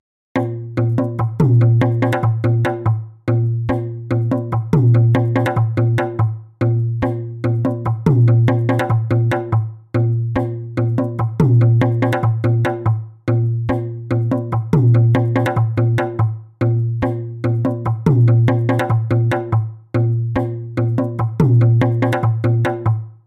Drum Modelling Examples
Pitch Pulse